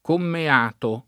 [ komme # to ]